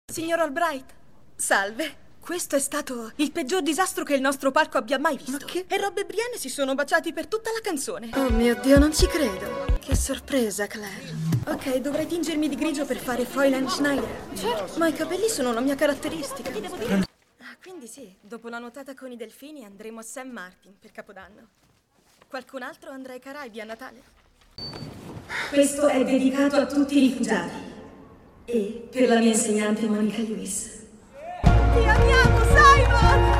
nel film "Tuo, Simon", in cui doppia Mackenzie Lintz.